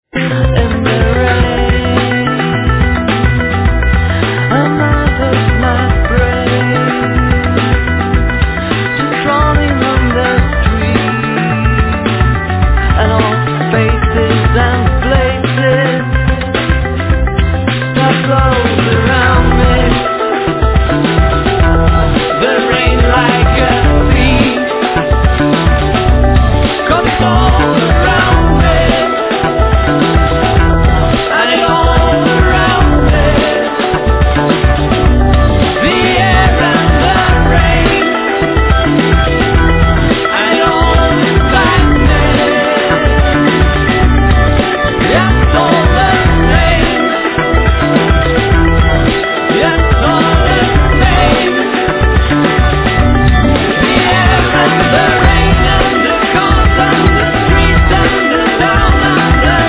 Vocals, Guitar
Guitar, Guitar synth
Drumas
Bass
Synthesizer, Guitar, Jew's harp, Violin, Percussions
Piano
Vocals, Mouth organ
Synthesizer, Machine-organ, Sitar, Violin, Percussions